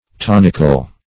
tonical - definition of tonical - synonyms, pronunciation, spelling from Free Dictionary Search Result for " tonical" : The Collaborative International Dictionary of English v.0.48: Tonical \Ton"ic*al\, a. Tonic.